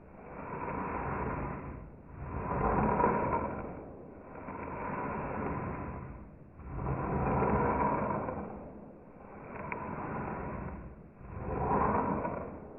Resampling
Wird der zeitliche Verlauf durch Änderung der Wiedergabegeschwindigkeit bzw. der Samplingrate verändert, so ändert sich auch die Tonhöhe und das Spektrum.
Finger_Tisch
finger_tisch_resample1.mp3